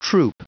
Prononciation audio / Fichier audio de TROUPE en anglais
Prononciation du mot troupe en anglais (fichier audio)
Prononciation du mot : troupe